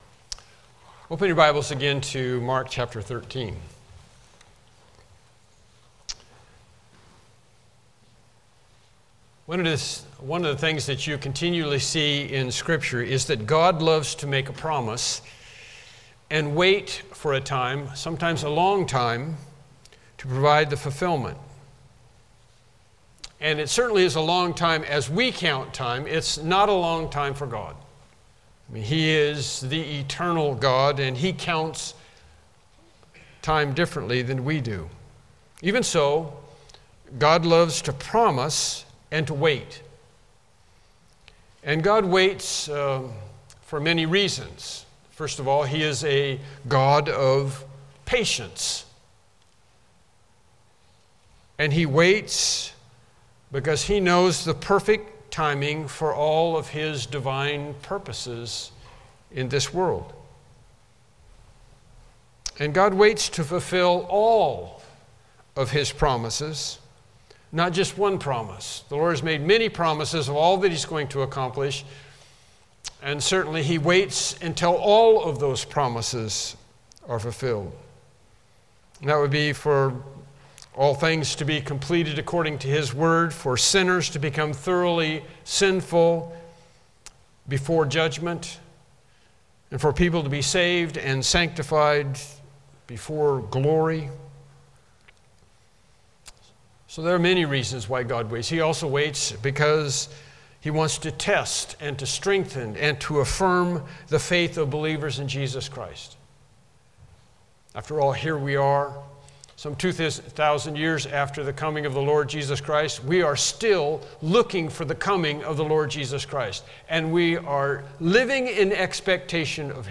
Passage: Mark 13:33-37 Service Type: Morning Worship Service « Lesson 4